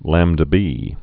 (lămdə-bē)